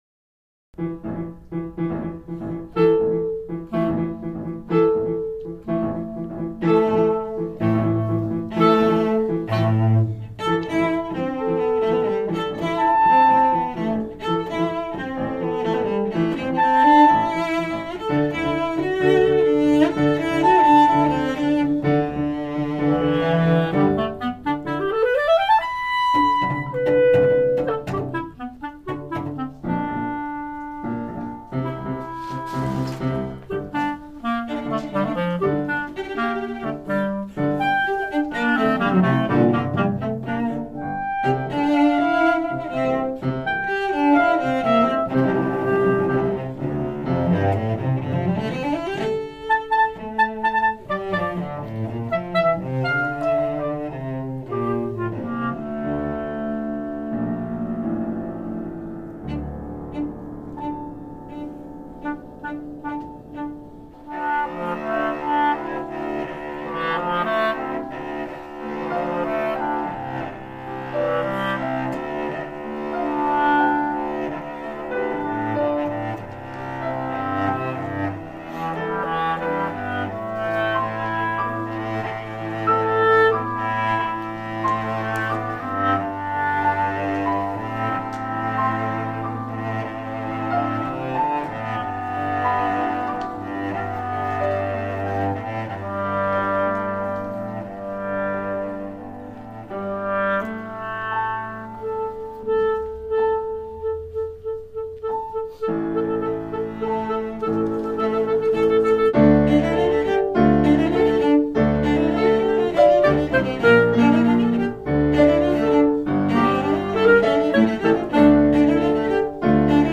I rearranged previous work (Galloping 2004 - sound track for the video game My Wife) for violin, clarinet and piano. Sight Reading on Oct 5th, 2006.
Dry version.